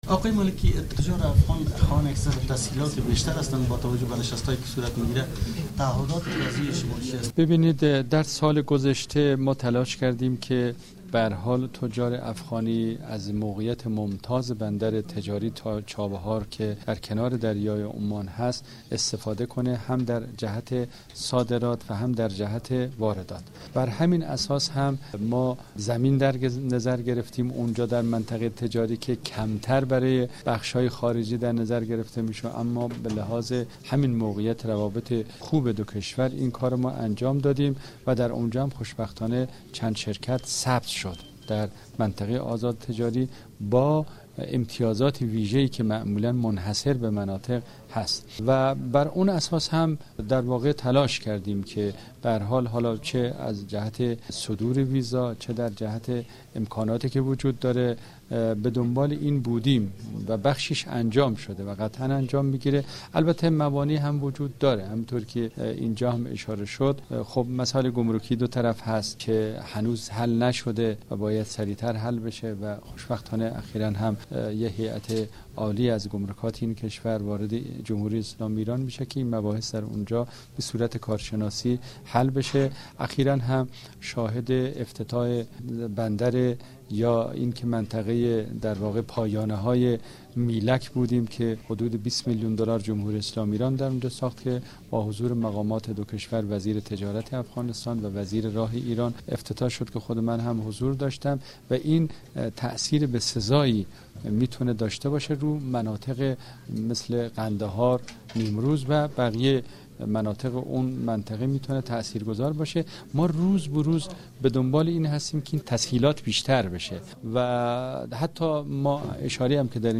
مصاحبه با فدا حسین مالکی سفیر ایران در کابل